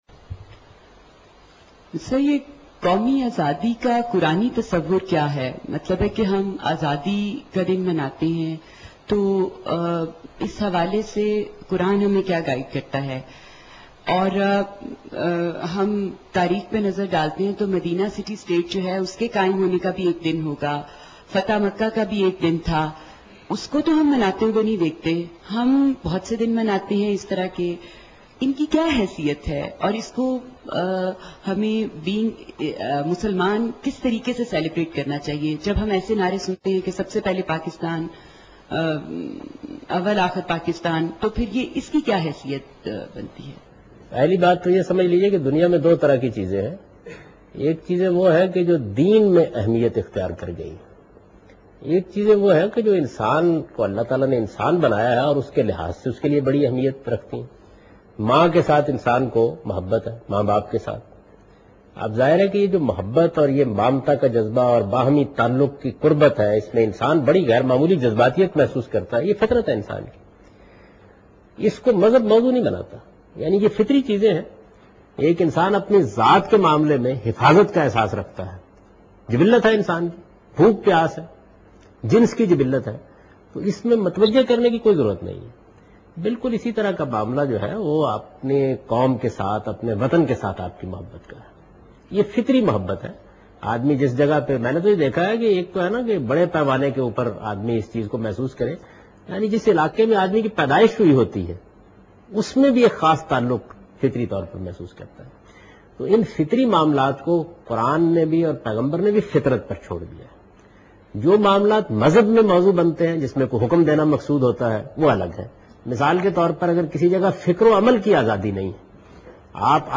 Javed Ahmed Ghamidi replying a question about celebrating Independence Day.